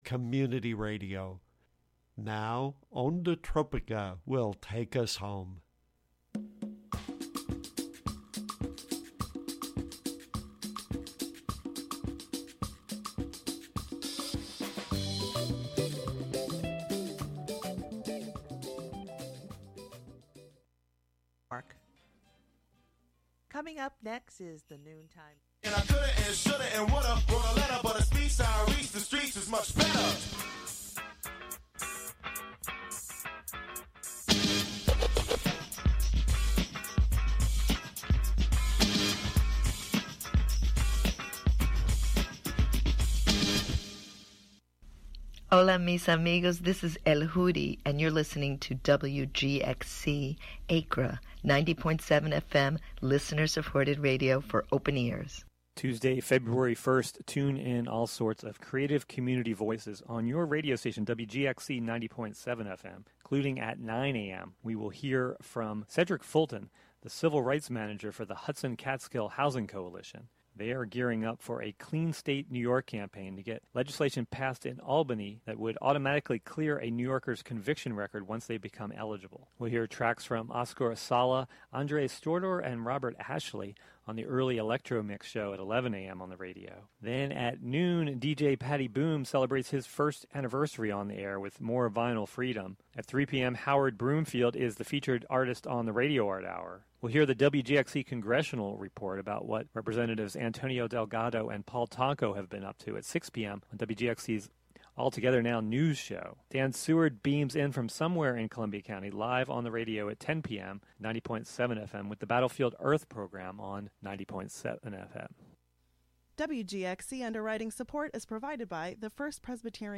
midwinter reggae break
mellow and uptempo tunes